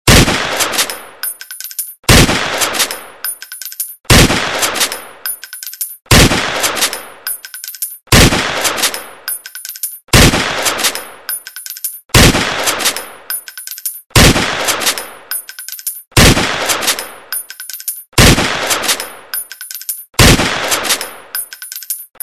Звук выстрела из ружья